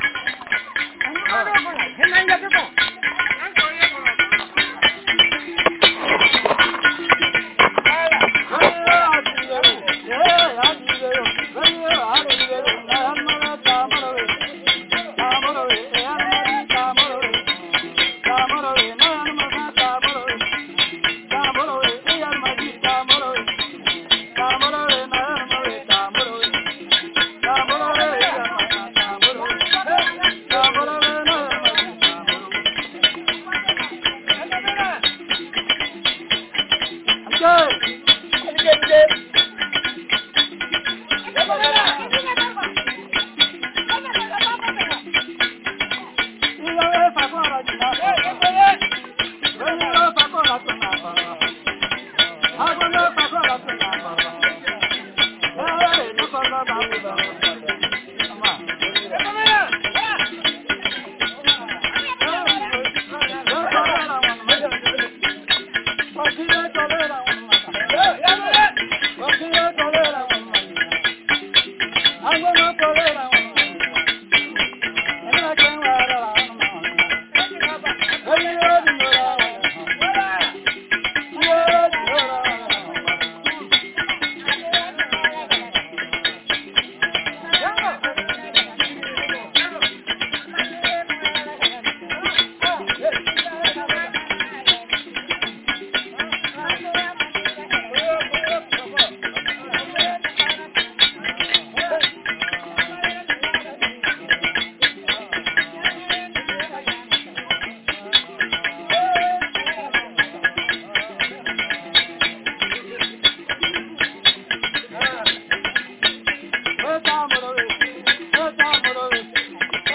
musicien aveugle
La musique de la flûte